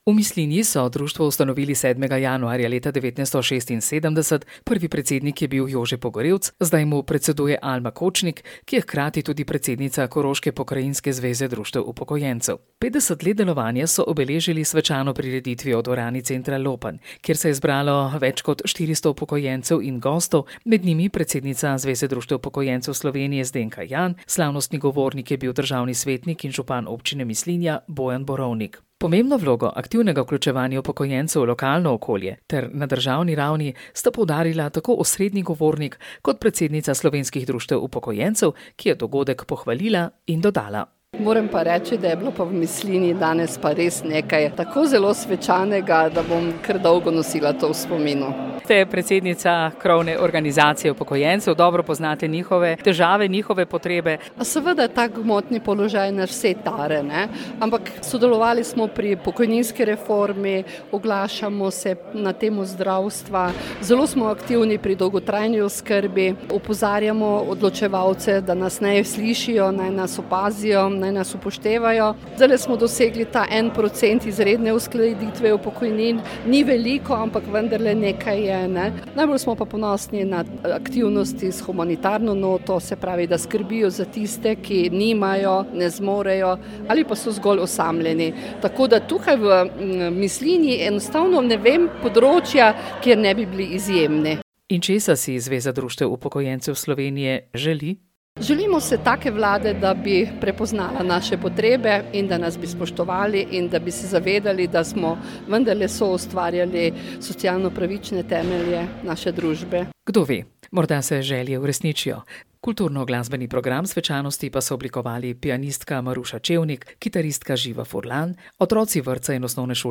Slavnostni govornik je bil državni svetnik in župan Občine Mislinja, Bojan Borovnik.